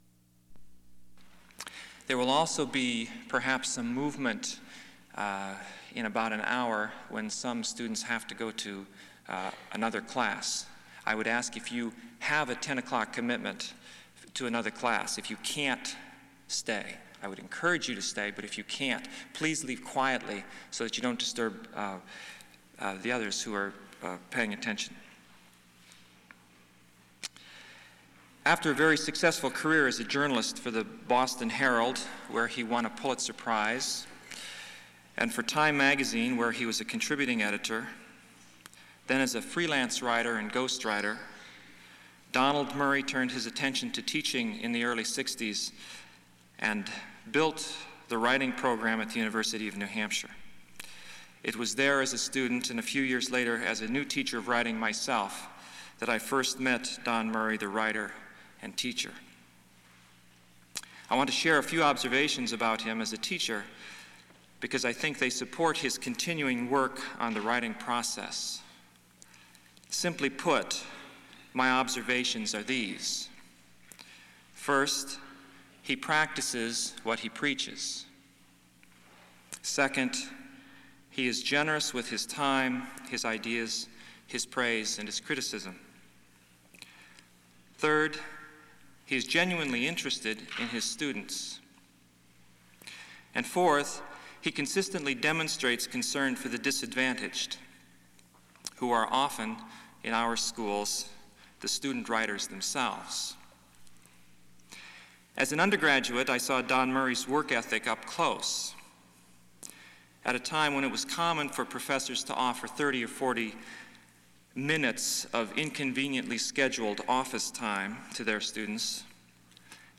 Wesley Chapel 10-3-91 Lecture, "Pushing the Edge," Donald Murray